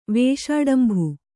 ♪ vēṣāḍambhu